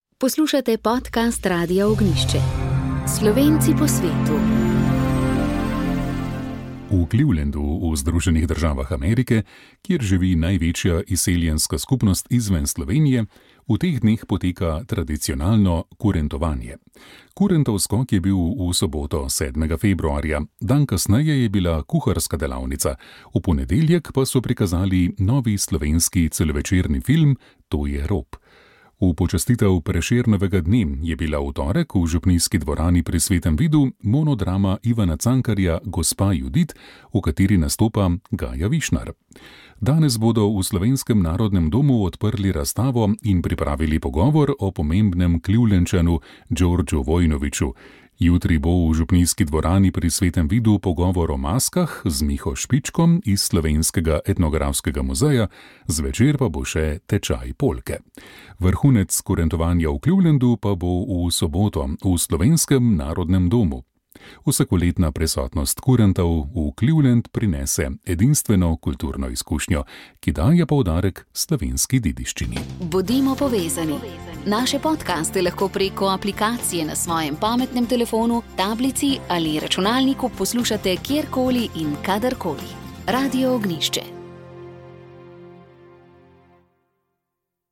Igralci Ljubiteljskega gledališča Petra Simonitija iz Celja so pripravili akademijo v počastitev 150-letnice rojstva in 90-letnice smrti generala in pesnika Rudolfa Maistra Vojanova.
tukaj pa lahko poslušate njegovo radijsko izvedbo.
Recital